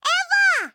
Cri d' Évoli dans Pokémon HOME .